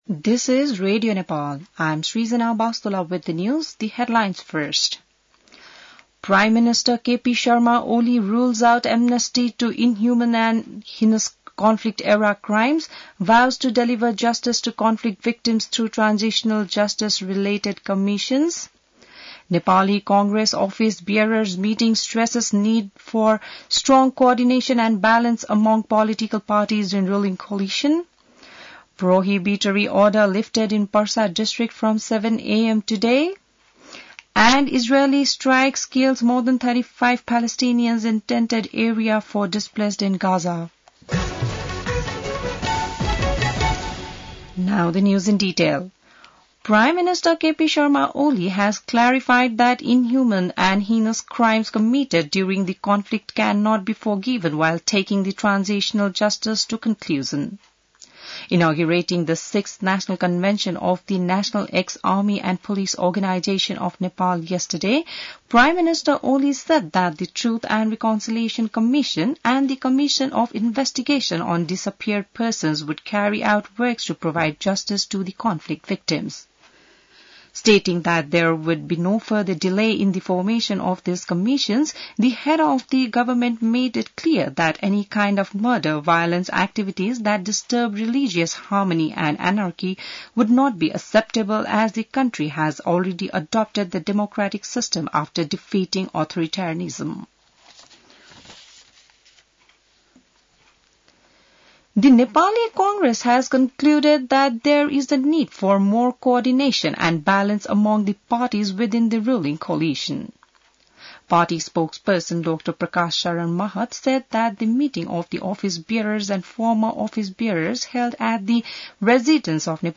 बिहान ८ बजेको अङ्ग्रेजी समाचार : ५ वैशाख , २०८२